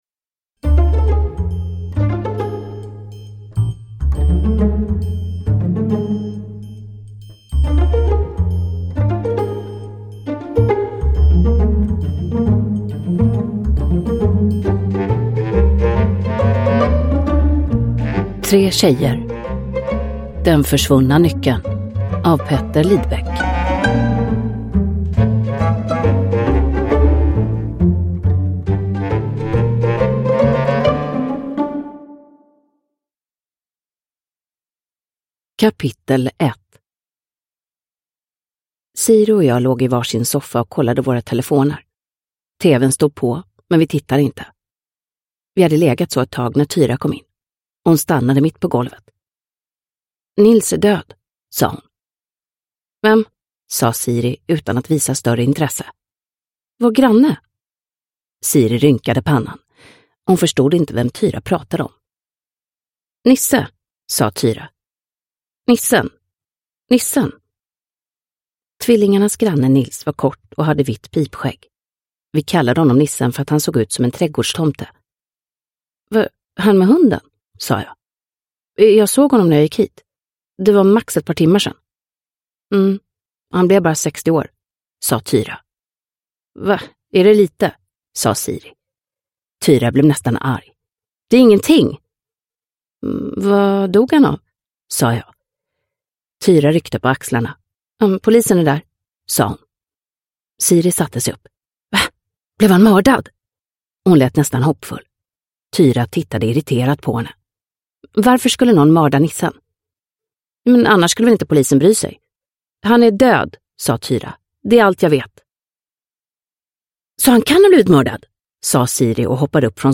Den försvunna nyckeln – Ljudbok – Laddas ner
Uppläsare: Mirja Turestedt